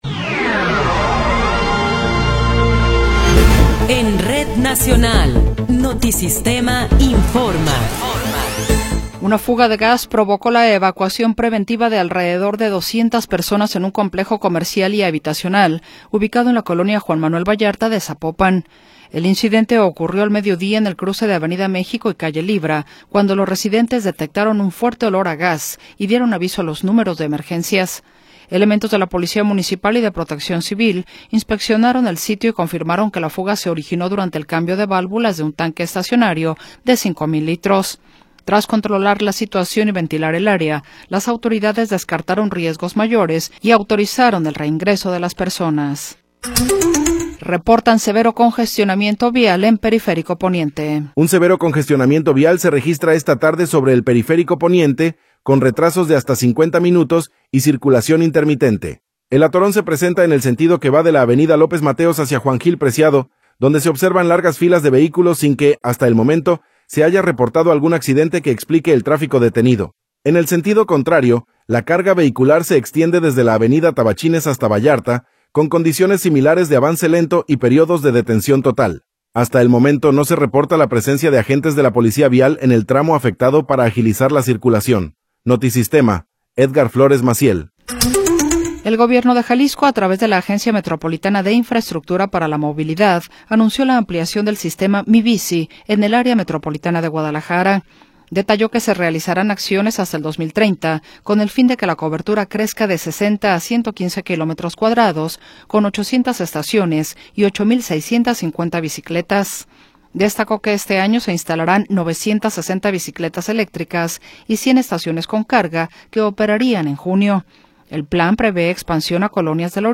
Noticiero 16 hrs. – 13 de Febrero de 2026
Resumen informativo Notisistema, la mejor y más completa información cada hora en la hora.